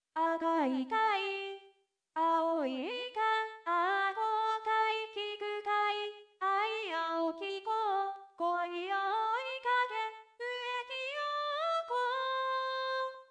デモを聞いてみるとまだ合成くささは残っているもののなかなかのものだ。
くやしいので「ア」～「コ」だけで歌を作ってみた。４小節では足りないので２度に分けて作りつなぎあわせた。